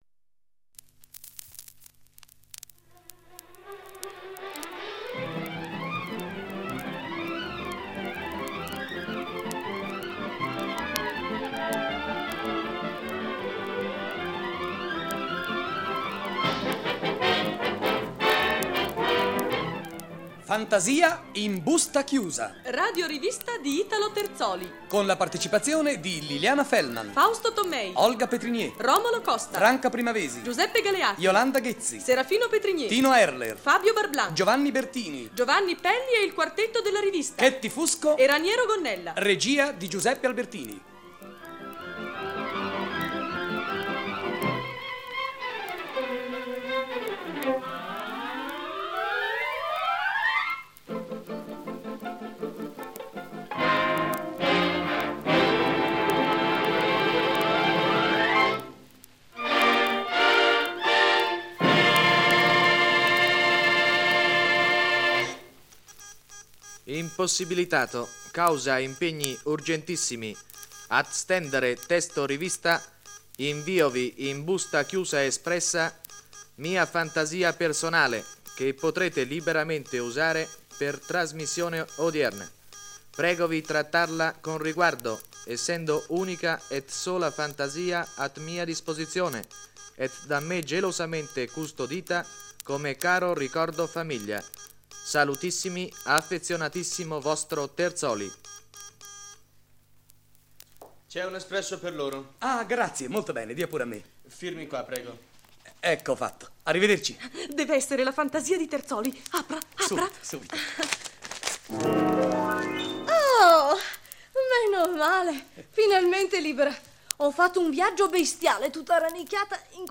Radio rivista di Italo Terzoli
Caratterizzate da un tanto fantasioso quanto serrato dialogo, essenziale al loro ritmo incalzante era l'inserimento dei brani cantati su temi delle canzoni in voga o di repertorio, su testo parodiato con gli accompagnamenti morbidi e arguti del pianoforte
La puntata scelta, andata in onda il 30 marzo 1950 è di Italo Terzoli e si intitola “Fantasia in busta chiusa".